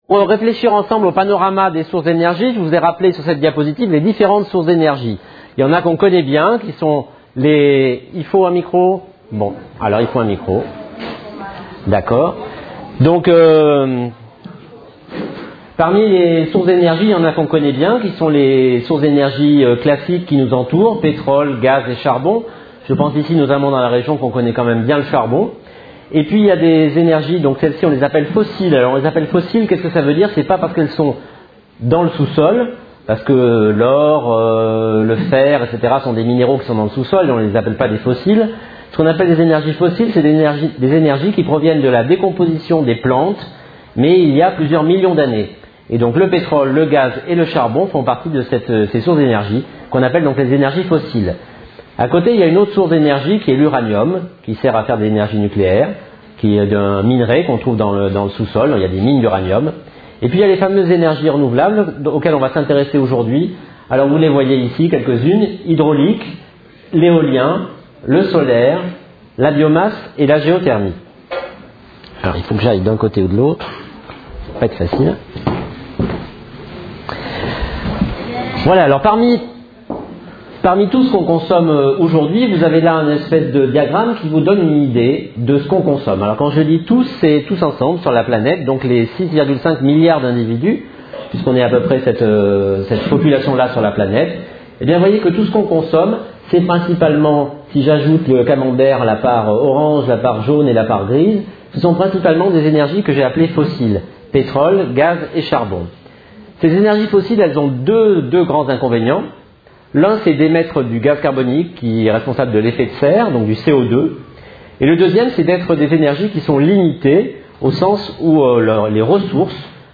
Une conférence de l'UTLS au Lycée